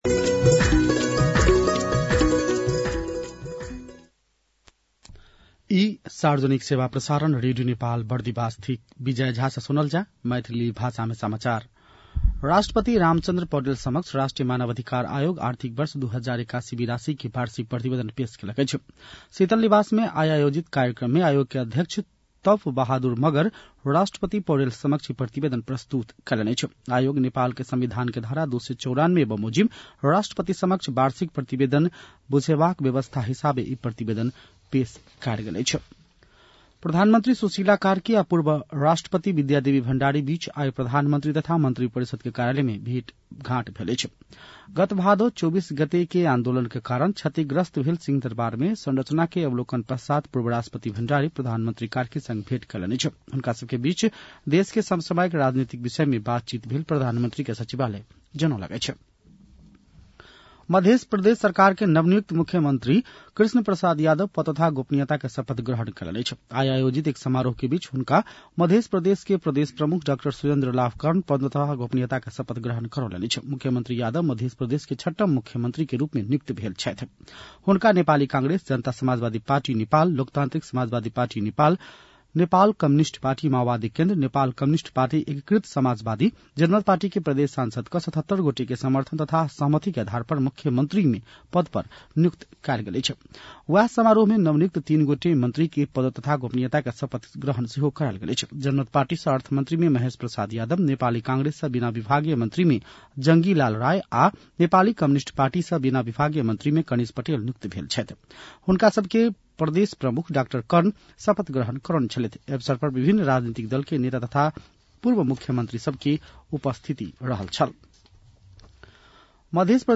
मैथिली भाषामा समाचार : २१ मंसिर , २०८२
6.-pm-maithali-news-1-1.mp3